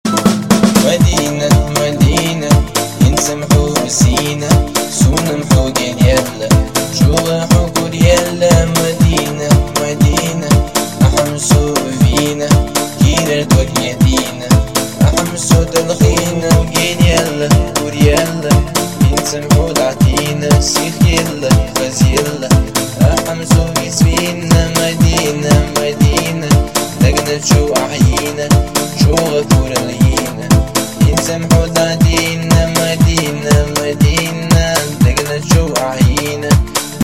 • Качество: 128, Stereo
восточные
кавказские
Нарезка романтичной кавказской песни.